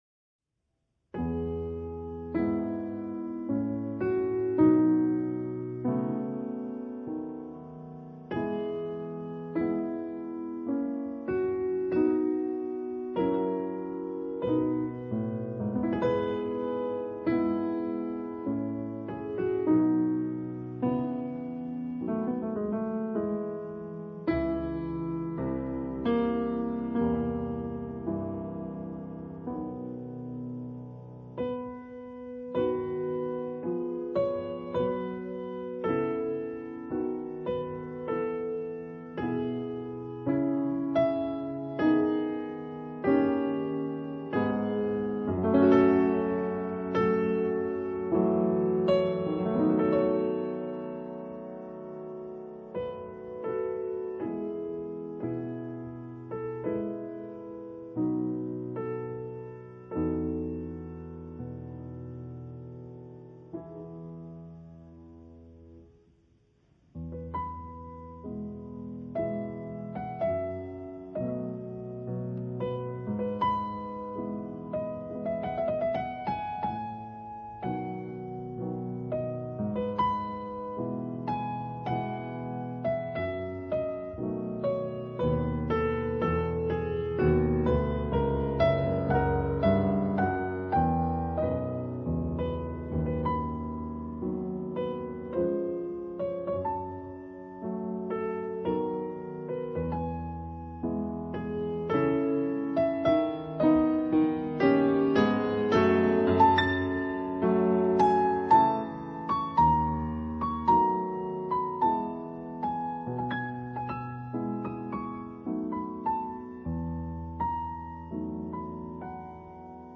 同時在演奏上，也更加端莊、古典。